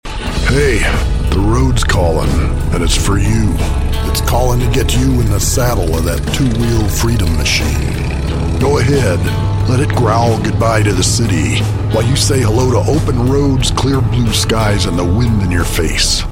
Male
a deep baritone voice with some grit and a tone of wisdom, authority, warmth and trust
Motorcycle Commercial - Radio